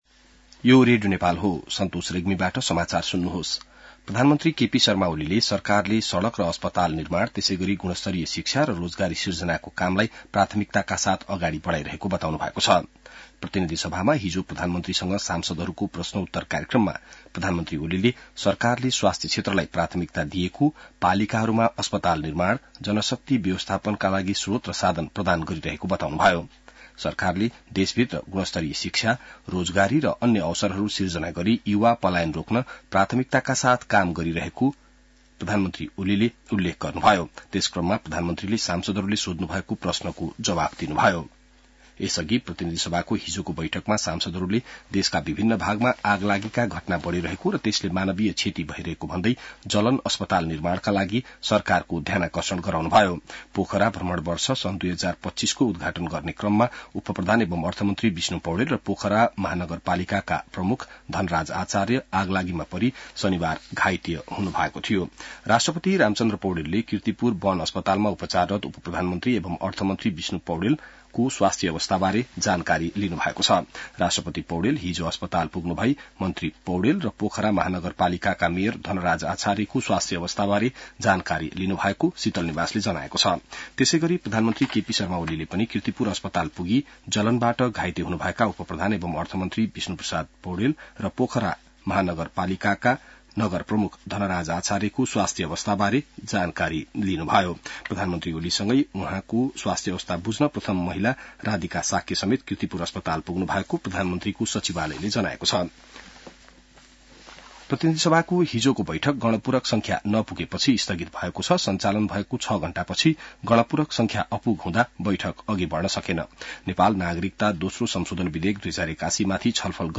बिहान ६ बजेको नेपाली समाचार : ६ फागुन , २०८१